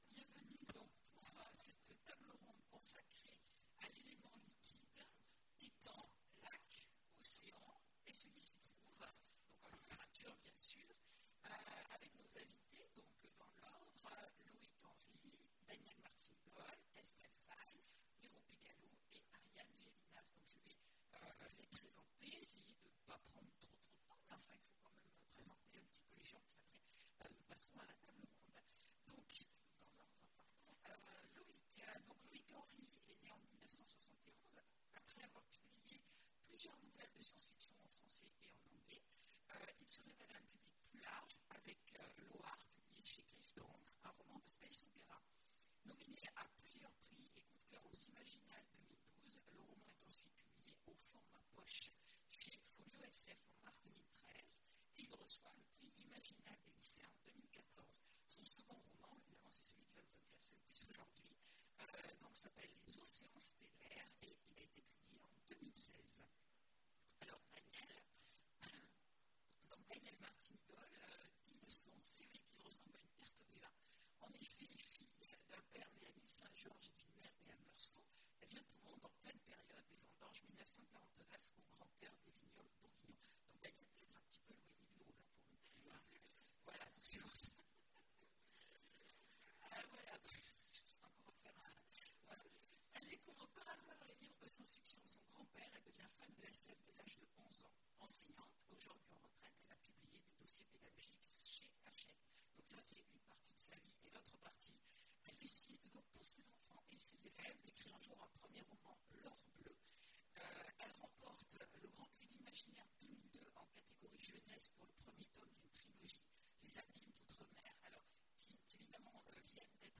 Imaginales 2017 : Conférence Étangs, lacs, océans… Et ce qui s'y trouve !